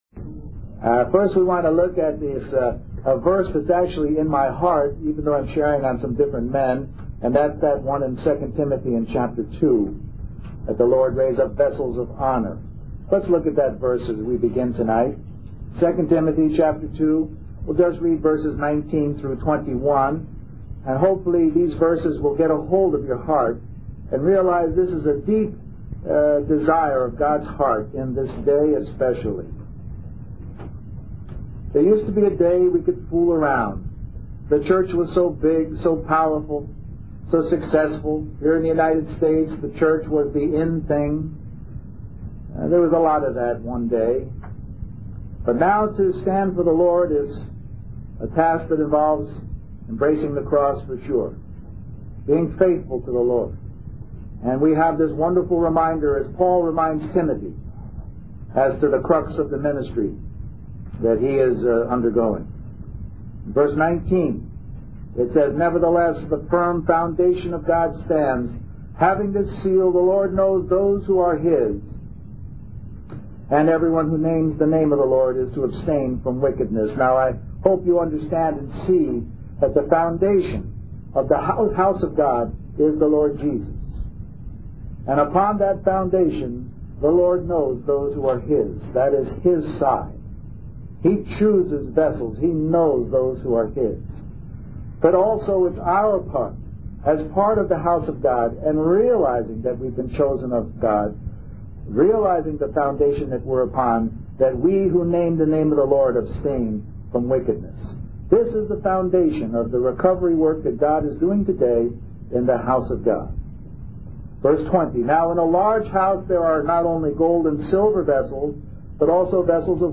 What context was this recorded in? Toronto Summer Youth Conference